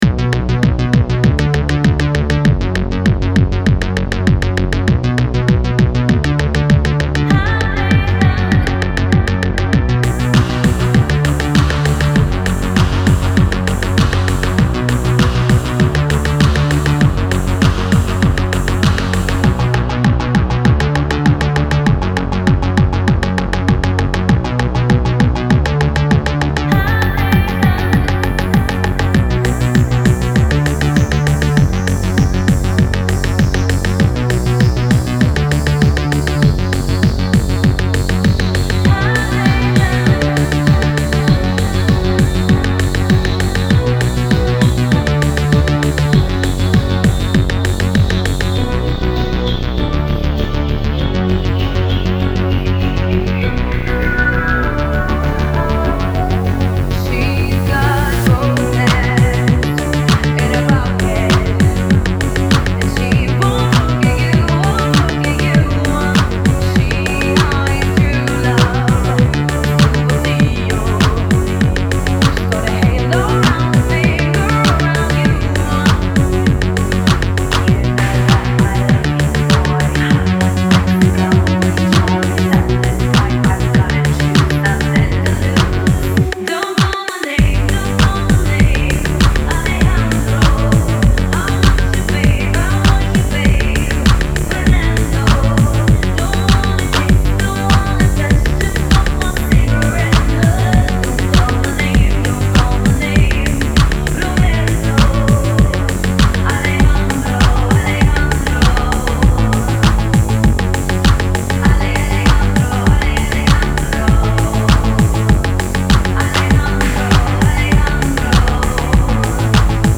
Italo remix